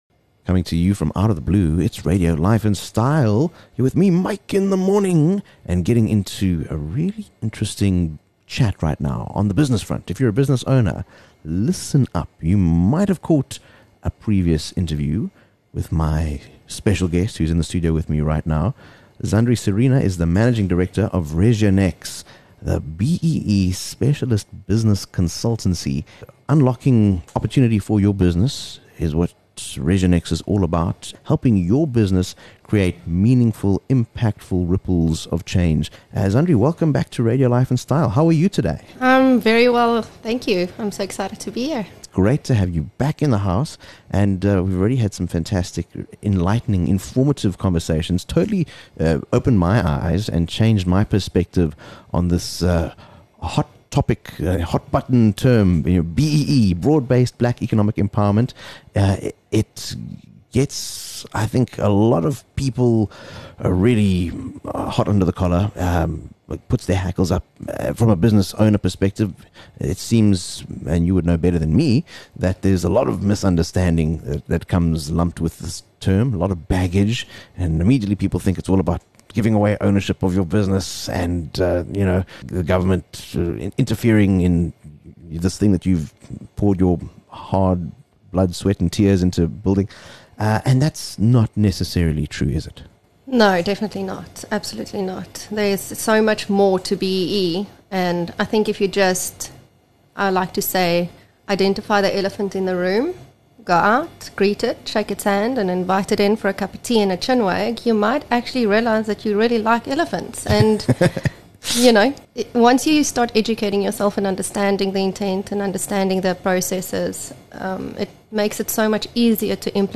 returns for another radio chat to demystify the world of Black Economic Empowerment and how your business can win by embracing it as part of a business strategy. Contrary to popular belief, being fully BEE compliant does not necessarily have to involve giving up direct business ownership/equity...